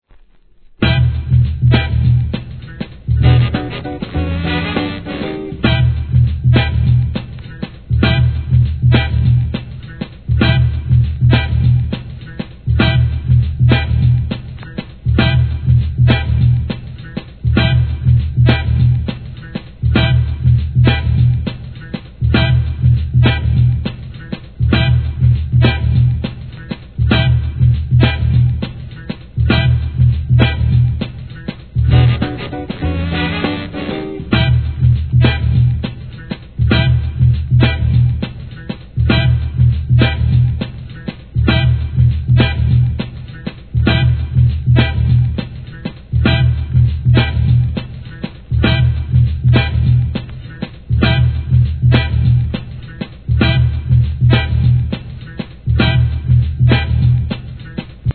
HIP HOP/R&B
FUNKYブレイク・ビーツ集!!